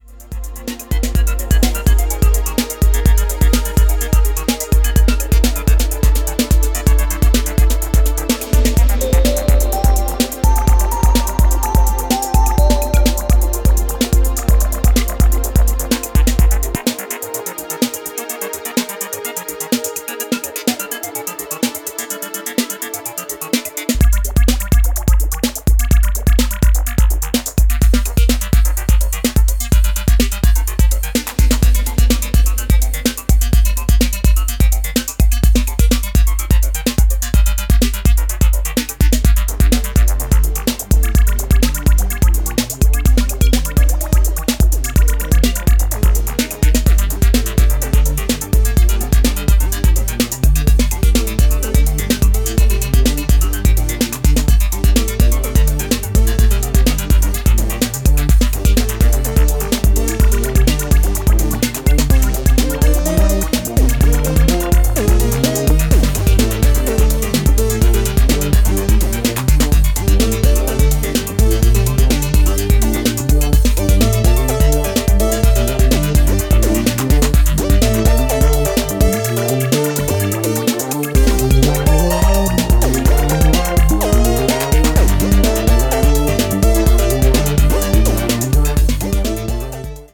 He has been experimenting with electronic music since 1986.